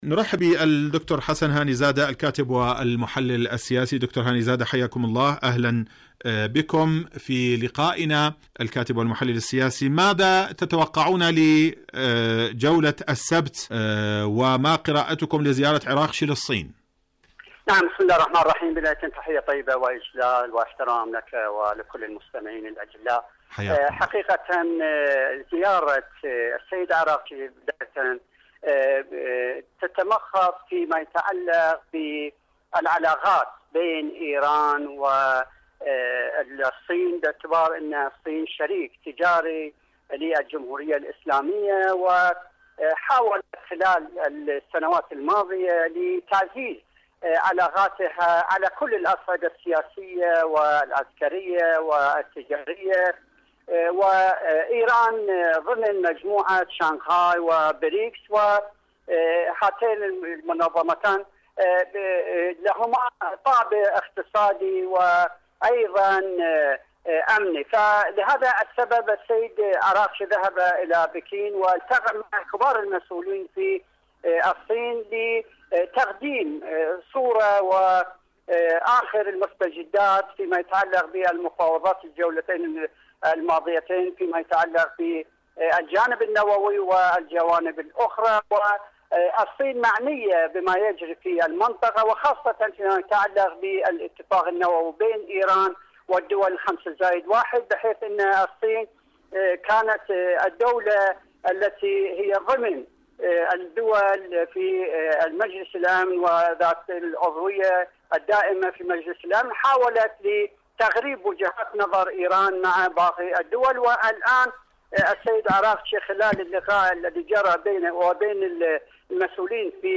مقابلة
إذاعة طهران- حدث وحوار: مقابلة إذاعية